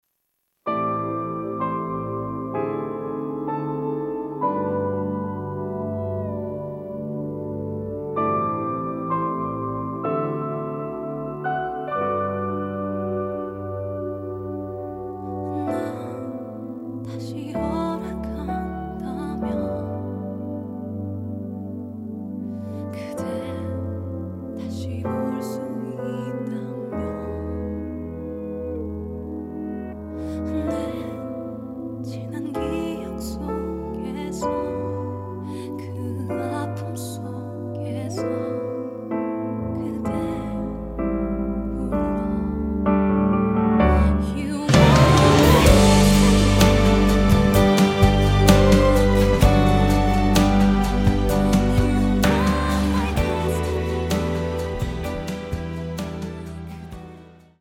음정 원키 3:52
장르 가요 구분 Voice Cut